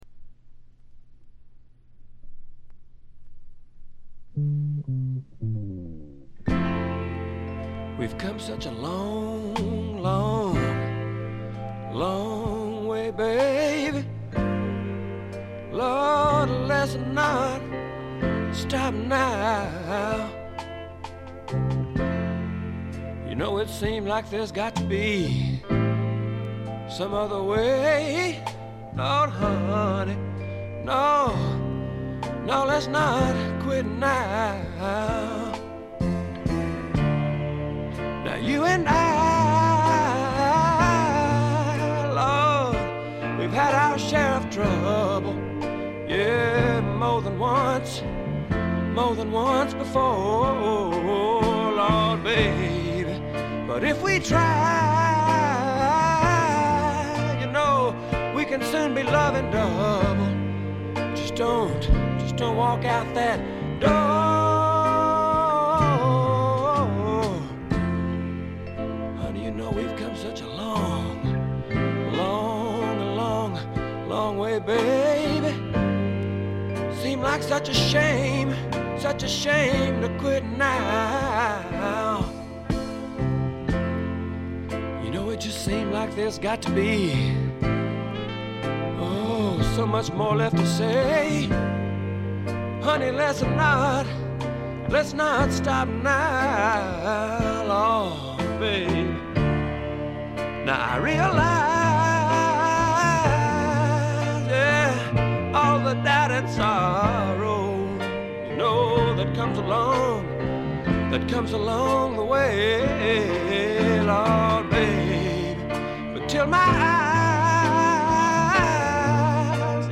部分試聴ですが、軽微なチリプチ少々。
あまりナッシュビルぽくないというかカントリーぽさがないのが特徴でしょうか。
試聴曲は現品からの取り込み音源です。
Guitar, Vocals, Piano, Vibes